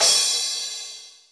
CRASH CS1 -S.WAV